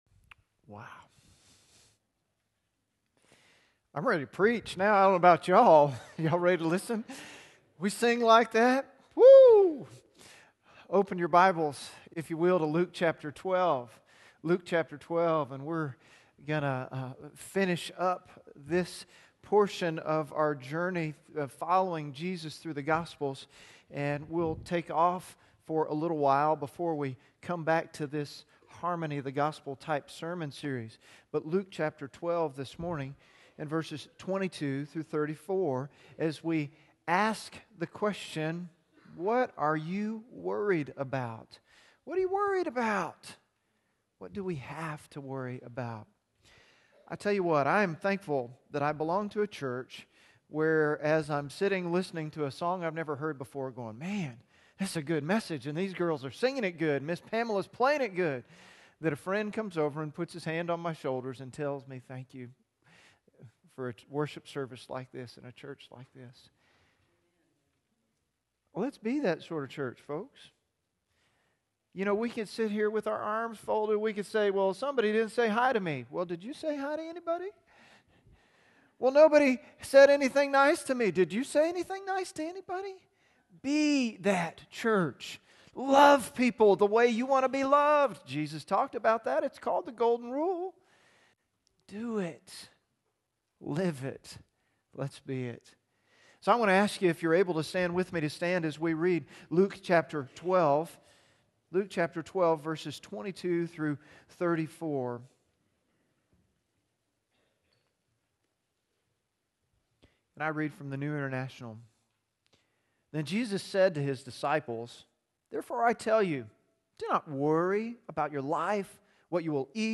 Luke 12:22-34 Sermon notes on YouVersion Following Jesus: What Are You Worried About?